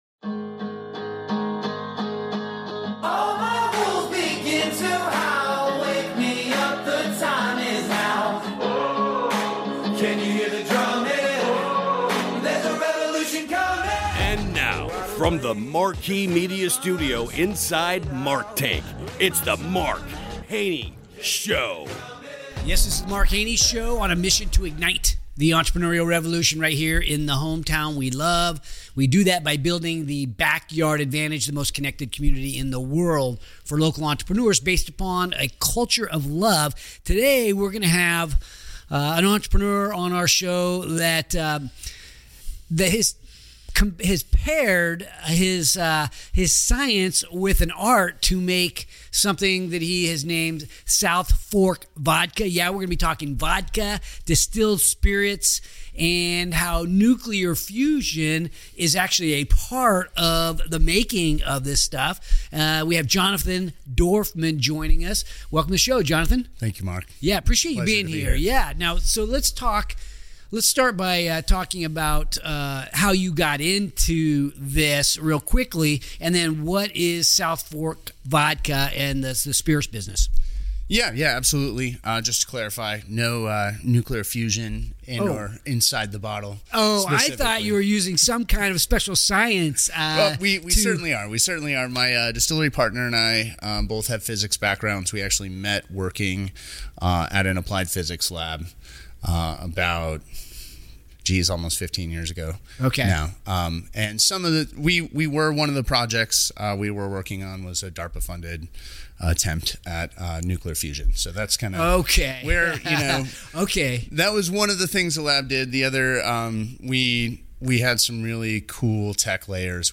Whether you're an aspiring entrepreneur or a vodka enthusiast, this interview offers valuable insights into innovation, business strategy, and the power of persistence. Don’t miss out on this inspiring conversation!